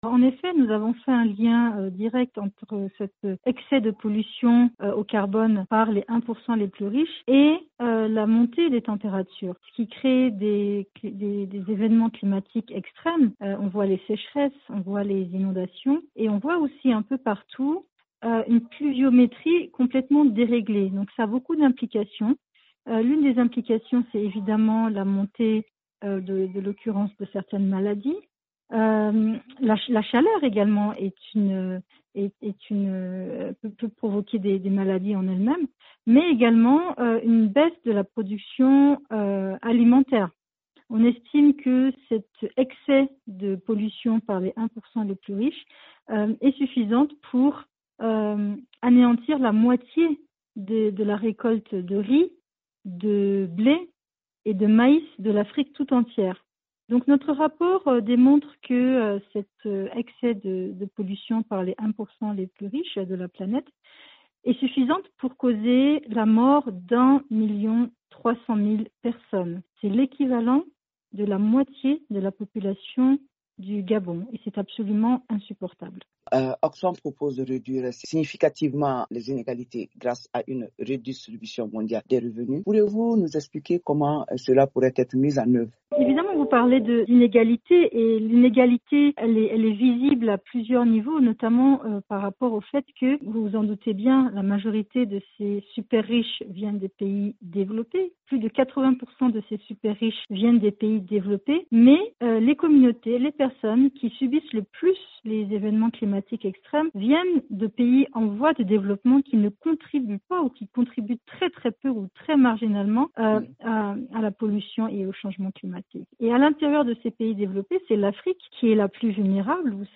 Reportages VOA